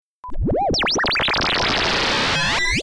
Space Bubble, Warp Sound Desin (Sound FX)
Space warp, screech, bubble sound, Science Fiction Sound Effects, Unidentified sounds
SpaceBubble_plip.mp3